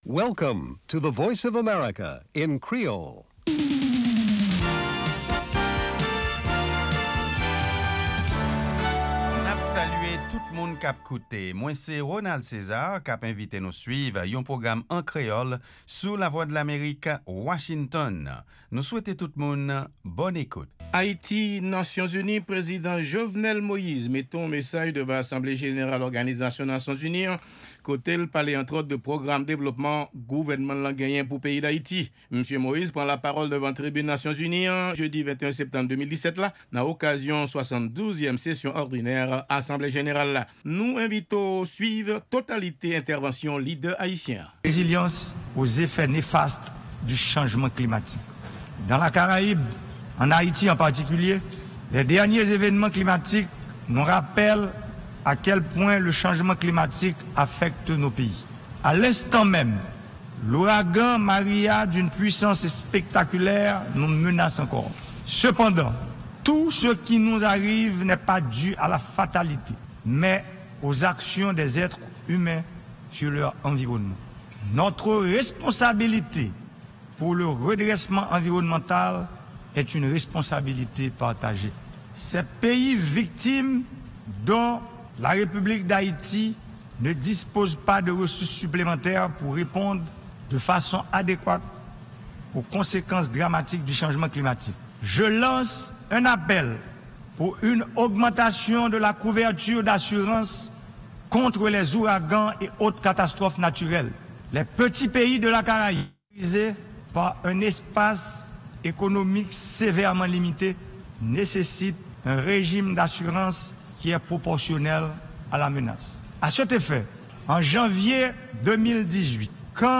Ayiti-ONU: Entèvansyon Prezidan Jovenel Moise nan 72èm Asanble Jeneral Nasyon Zini an nan New York.
Se 3èm e dènye pwogram jounen an, avèk nouvèl tou nèf sou Lèzetazini, Ayiti ak rès mond la. Pami segman ki pase ladan yo e ki pa nan lòt pwogram yo, genyen Lavi Ozetazini ak Nouvèl sou Vedèt yo.